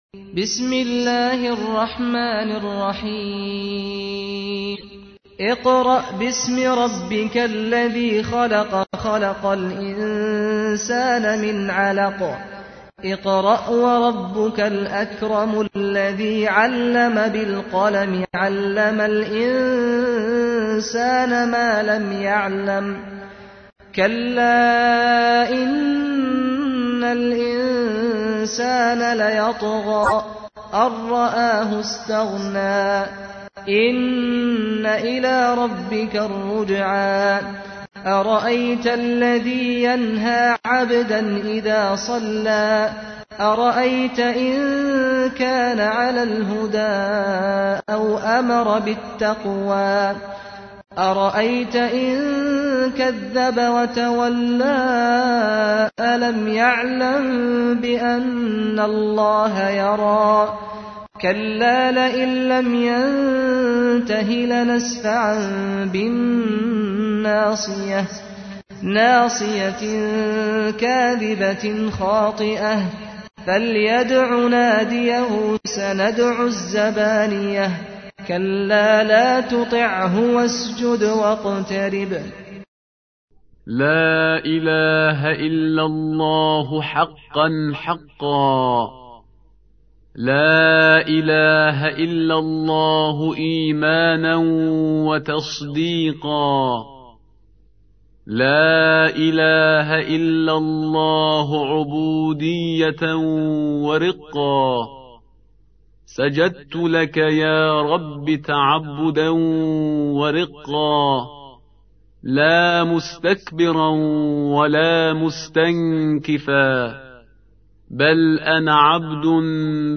تحميل : 96. سورة العلق / القارئ سعد الغامدي / القرآن الكريم / موقع يا حسين